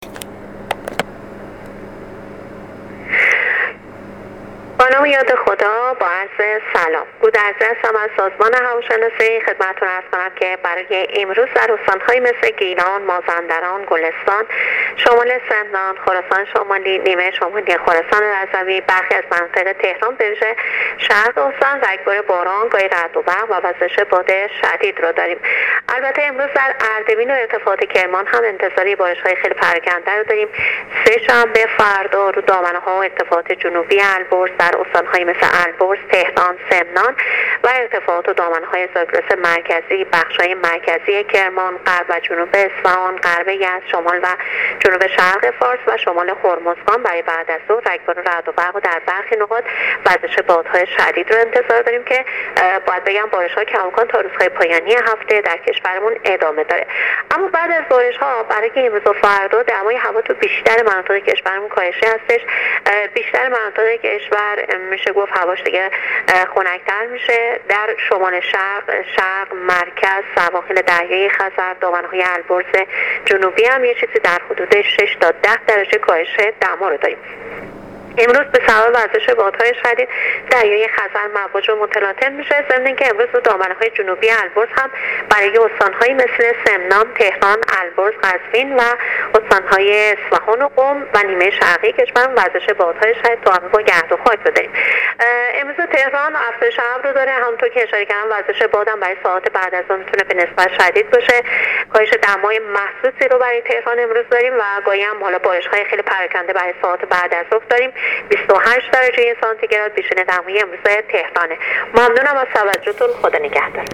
گزارش رادیو اینترنتی از آخرین وضعیت آب‌‌و‌‌‌هوای ۶ مهر